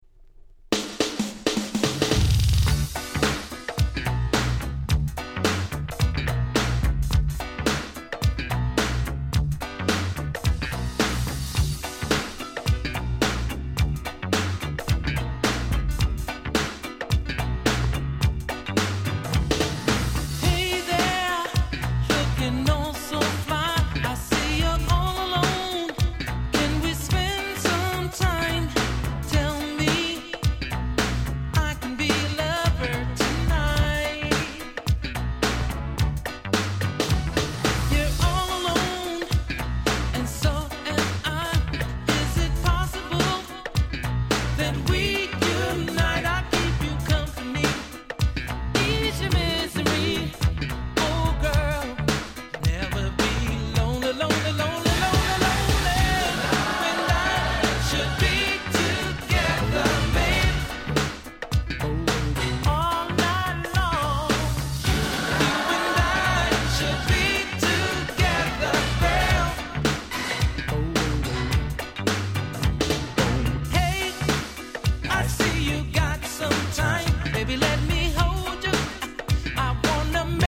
90' Nice New Jack Swing/R&B LP !!
シングルカットされたA-3を始め、ハネハネなNice New Jack Swing盛り沢山！